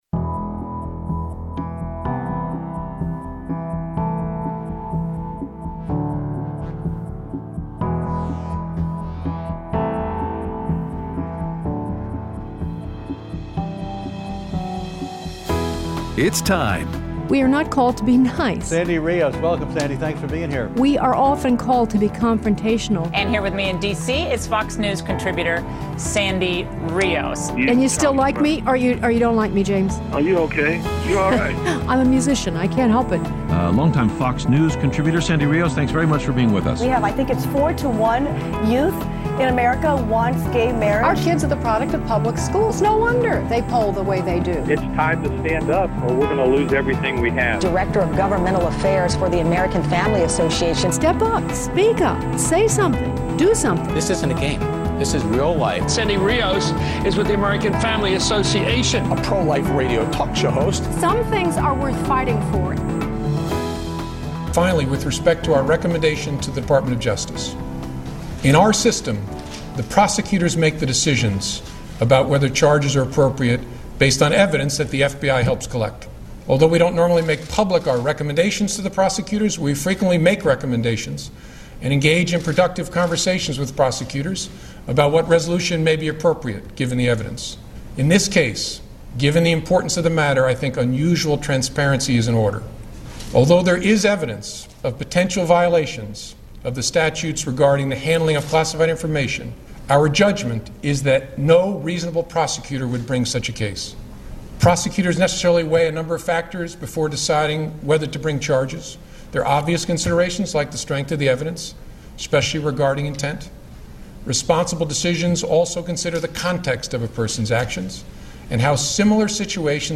No indictment for Hillary and Brexit interview with Amb. John Bolton - 7-6-16